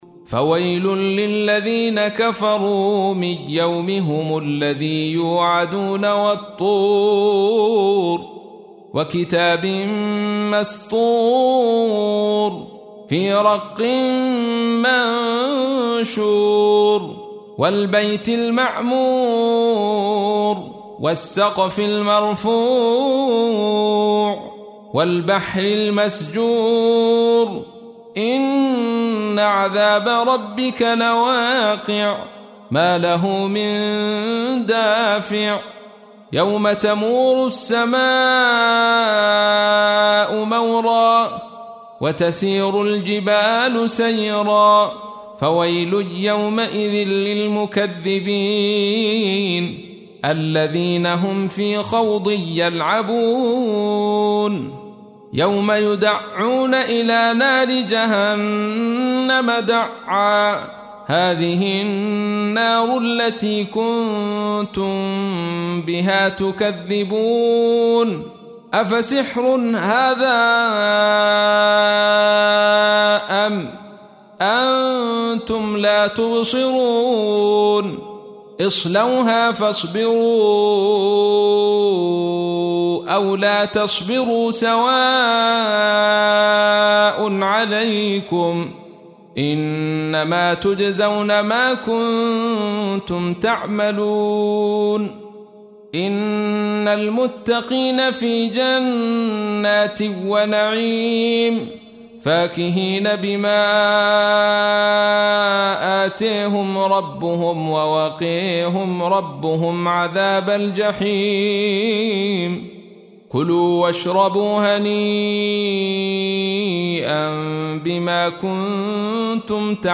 تحميل : 52. سورة الطور / القارئ عبد الرشيد صوفي / القرآن الكريم / موقع يا حسين